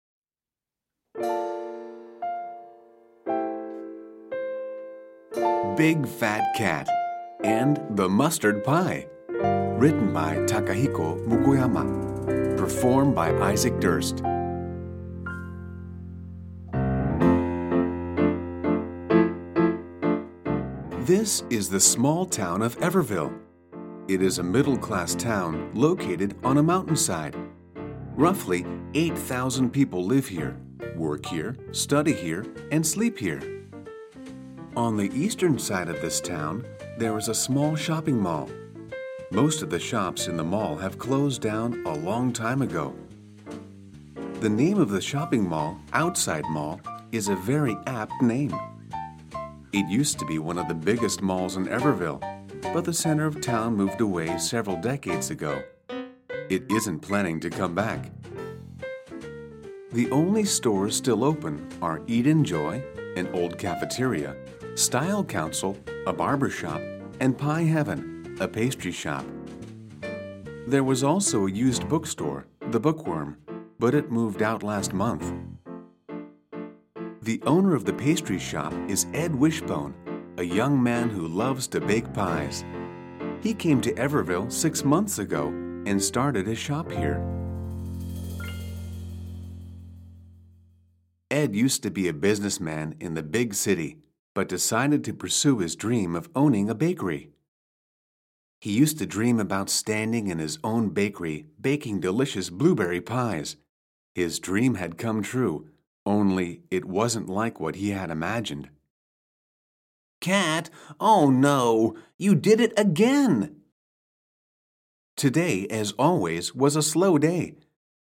빅팻캣 오디오북(CD 4장)